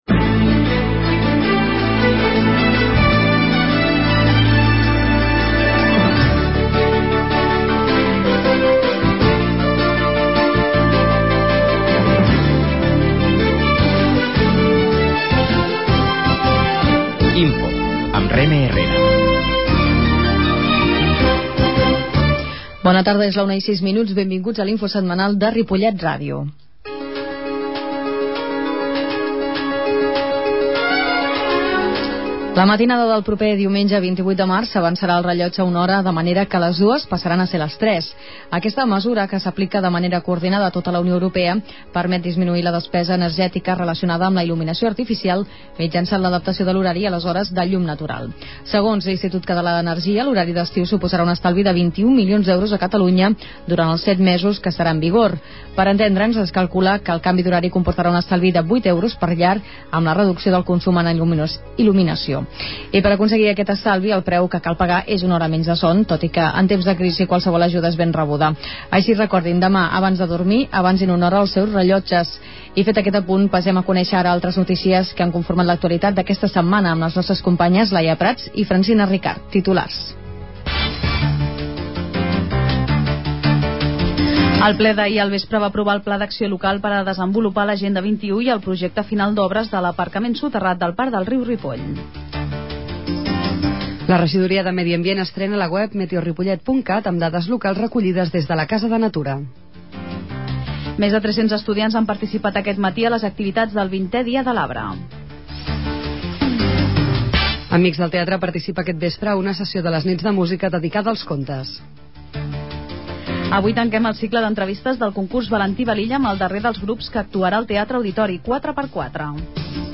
La qualitat de so ha estat redu�da per tal d'agilitzar la seva desc�rrega.